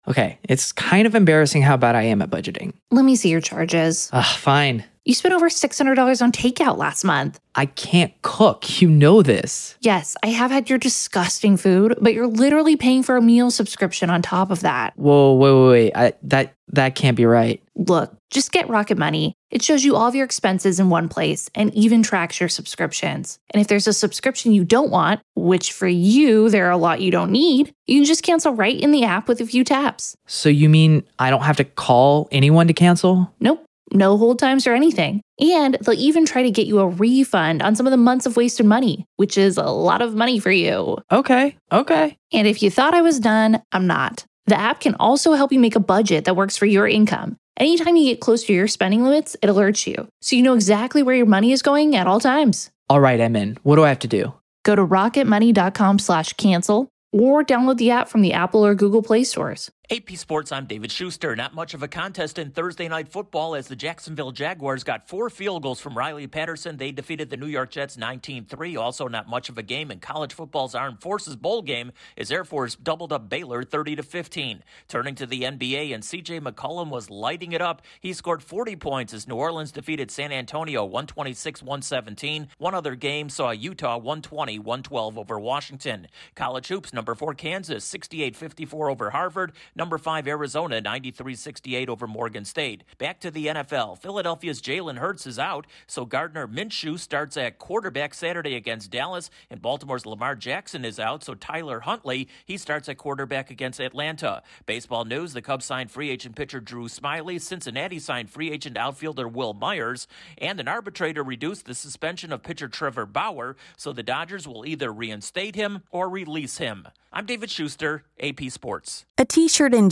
The Jaguars take down the Jets, Air Force wins over Baylor, Kansas beats Harvard in college action. Corrrespondent